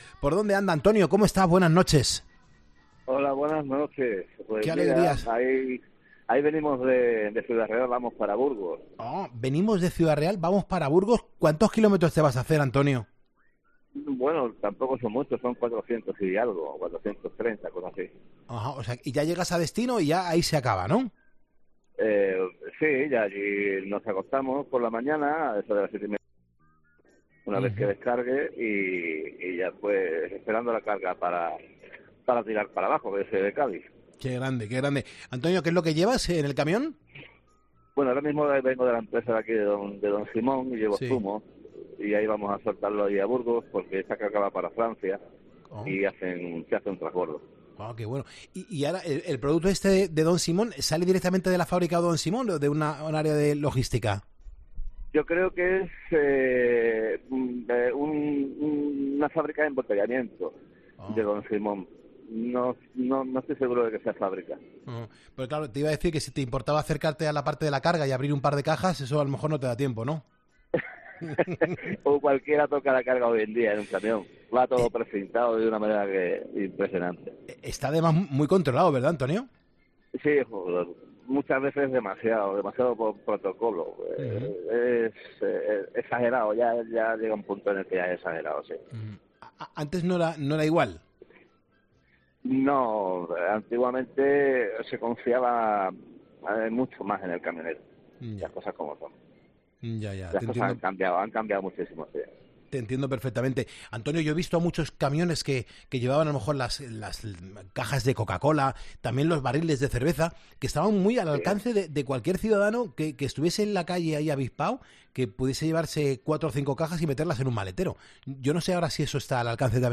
Un camionero